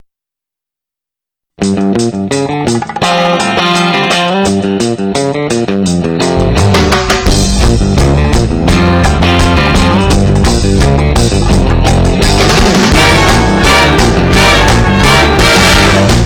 2.8MB, captured at 44KHz in 16bit stereo